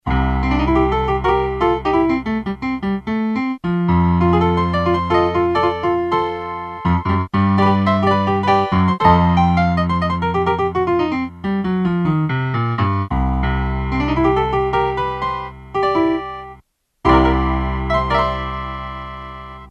Here's an MP3 example, using the default acoustic piano instrument number 1; you can hear the last chord going intermittent, but the rest of the notes pretty much behaved themselves, and I didn't have to do twelve takes to make one that sounded OK.
The sound selection is pretty low-fi, but could be worse.